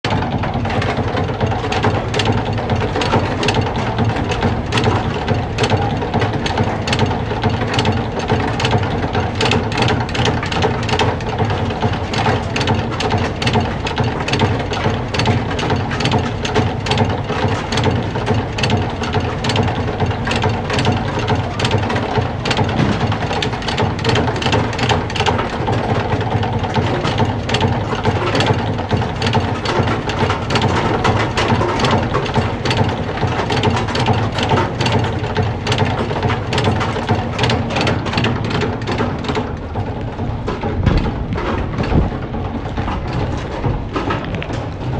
Therefore I decided to explore synths with lots of delay and reverb and muffled piano keys, a common place to begin for ambient music.